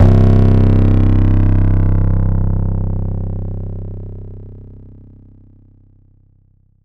SUB BOOM80.wav.wav